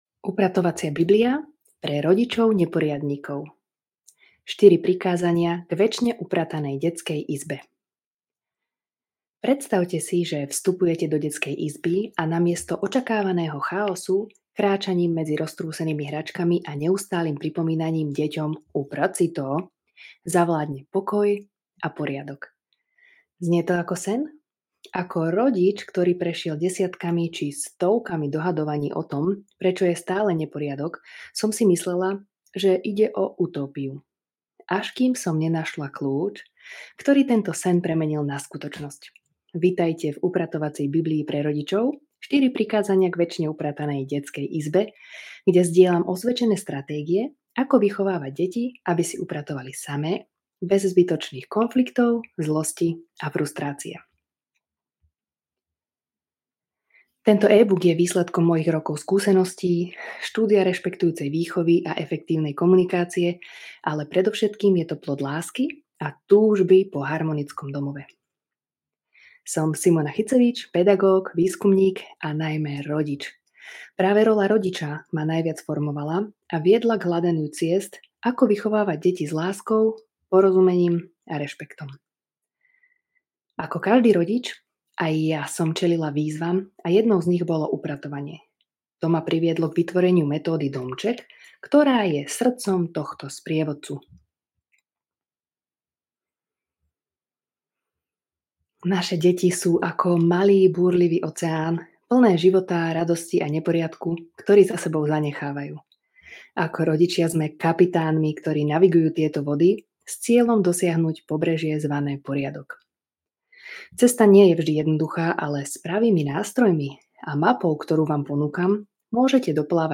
Upratovacia Biblia (Audio Kniha)
Upratovacia-Biblia-Audio-Kniha.mp3